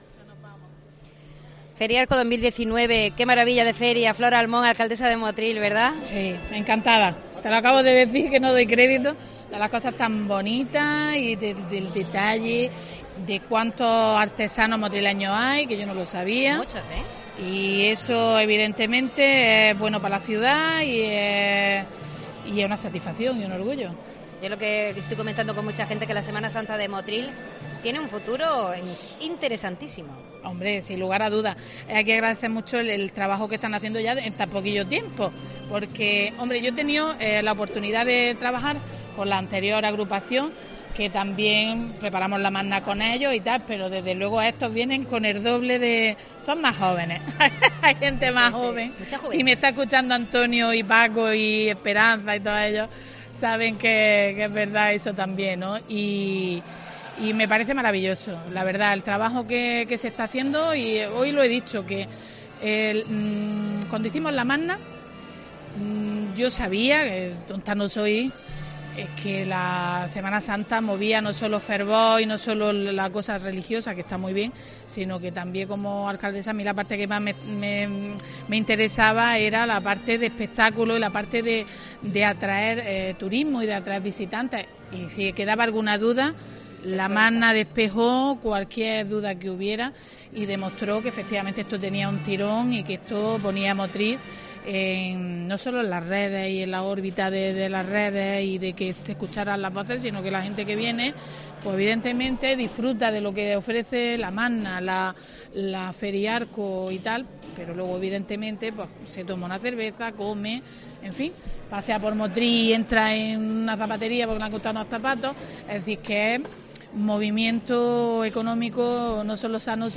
La alcaldesa de Motril anima a toda la ciudadanía a visitar FERIARCO